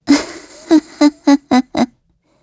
Gremory-Vox-Laugh_kr.wav